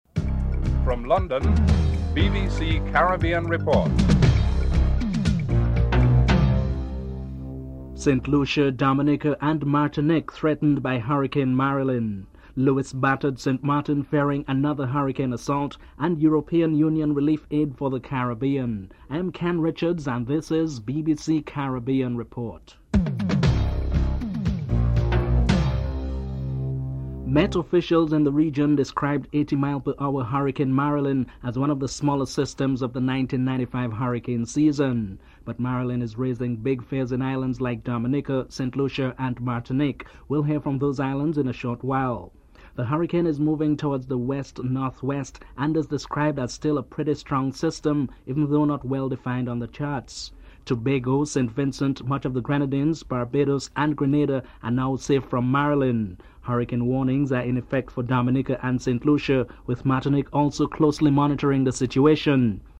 The British Broadcasting Corporation
6. Recap of top stories (14:39-15:06)